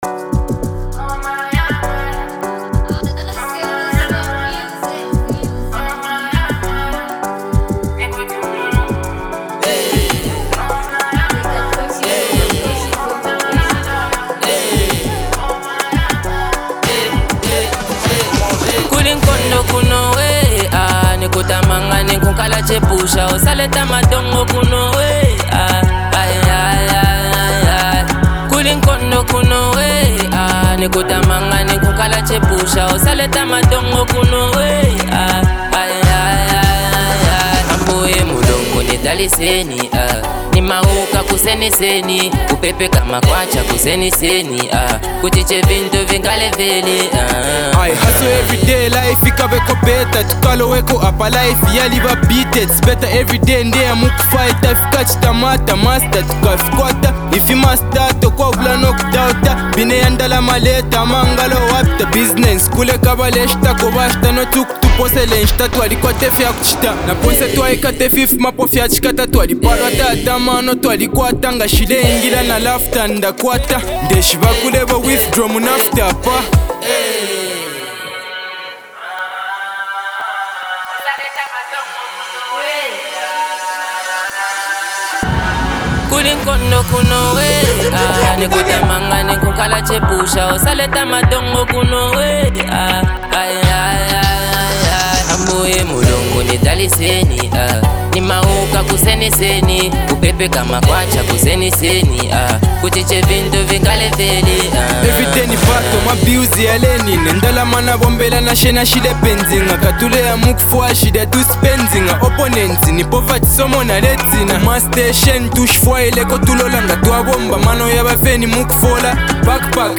raw, street-inspired energy
bold, almost militant delivery that commands attention
smooth, streetwise verses that add depth and relatability
For fans of Zambian hip-hop and Afro-fusion